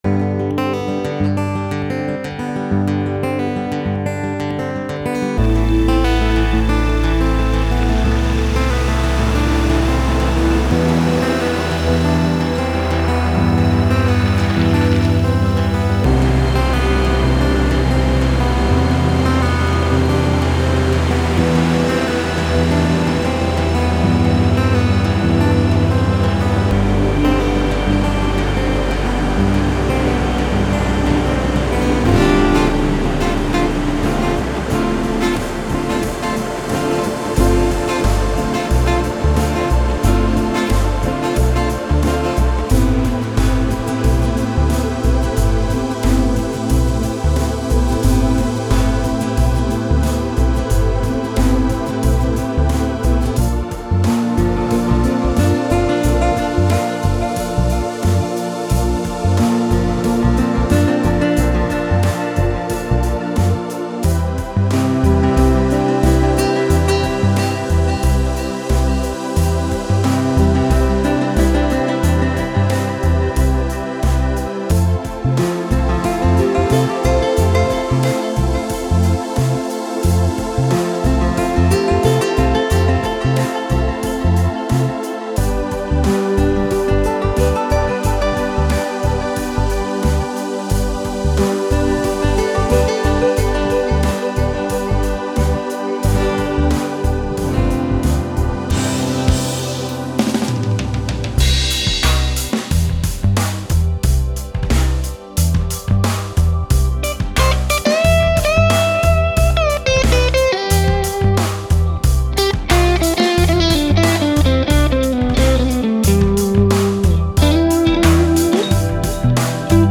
με chillout διάθεση